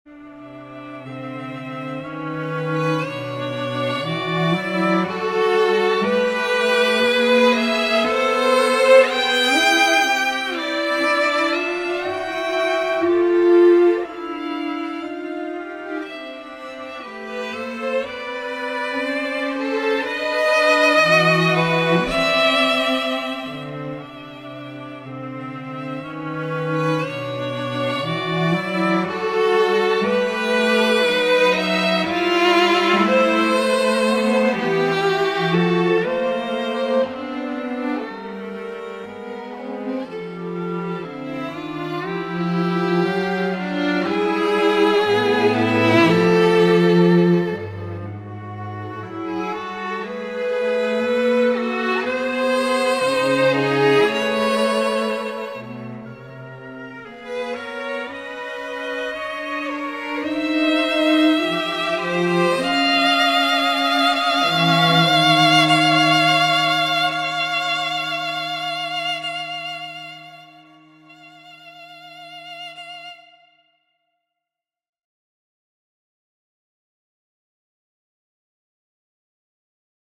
Persichetti Exercise 6 - 27 for String Trio
I've moved on to Chapter 6 of Persichetti's "20th Century Harmony" which is about secundal chords and clusters.